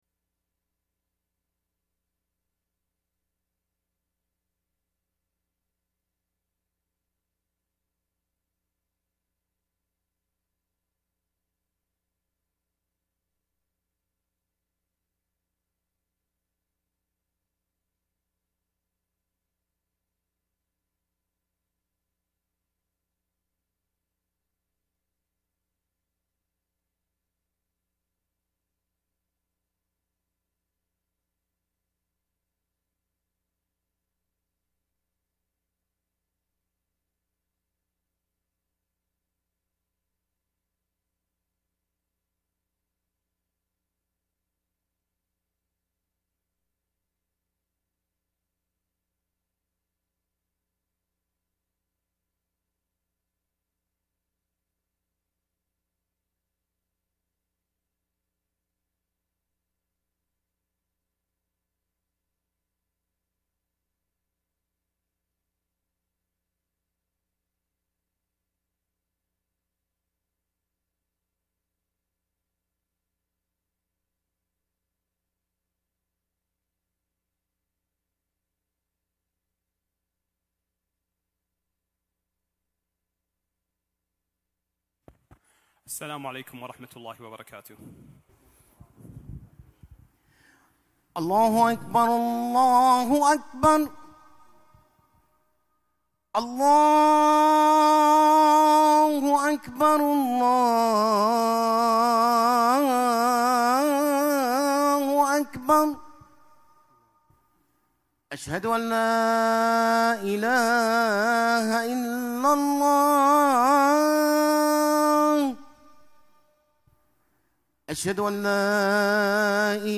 Friday Khutbah - "The Two Blessings"